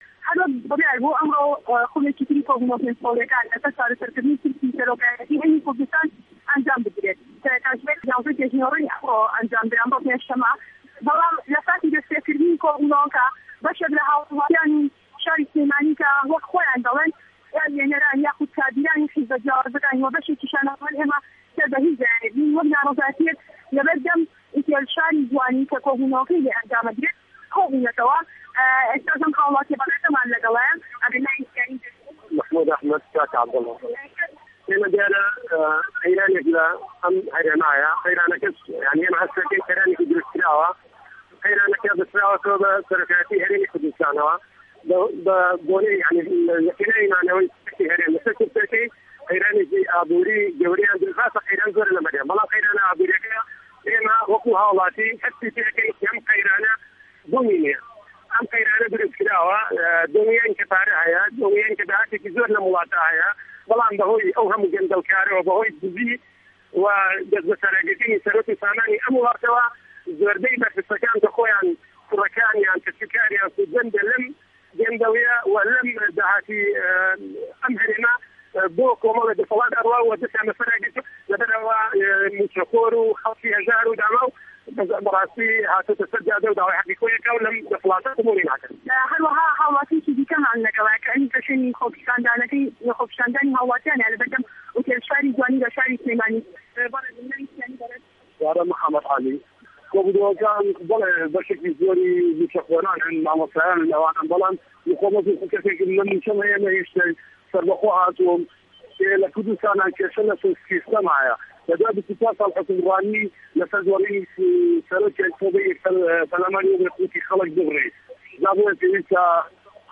ڕاپـۆرتێـکی دەنگی